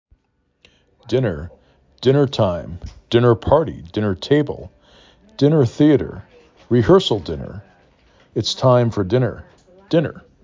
6 Letters, 2 Syllables
din ner
d i n er